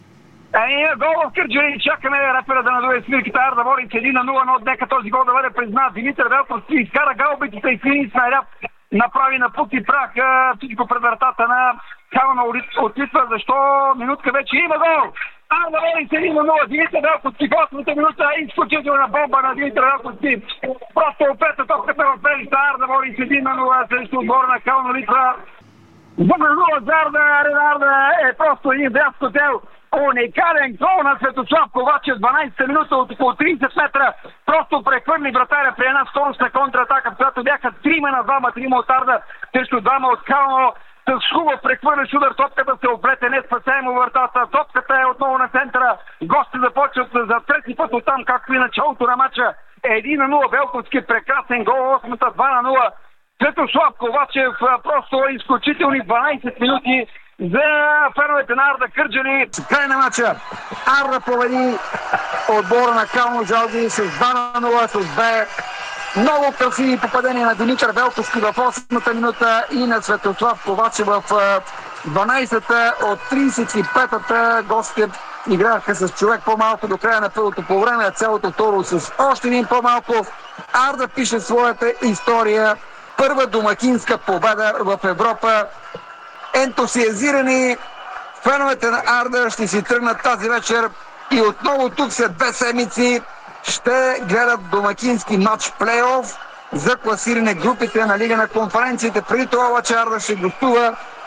Красивите голове на Арда в радиоефира на Дарик (АУДИО)